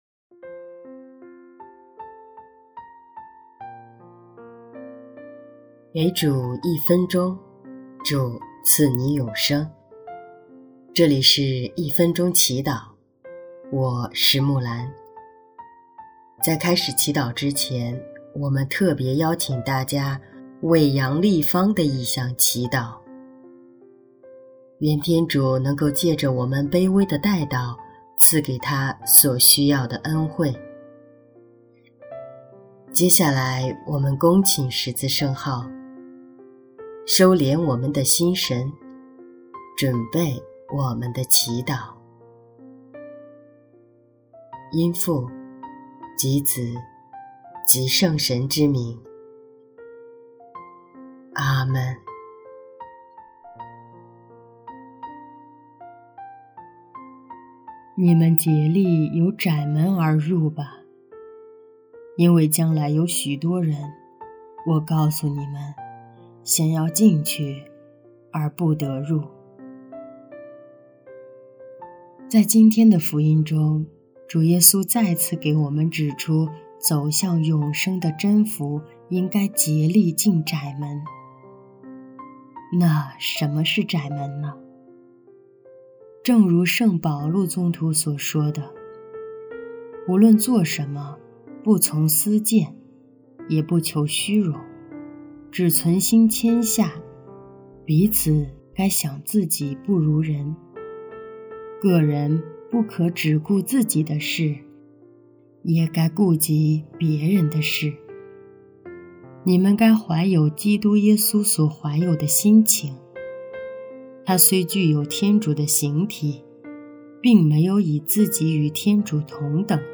音乐：第二届华语圣歌大赛歌曲《离了你就一无所有》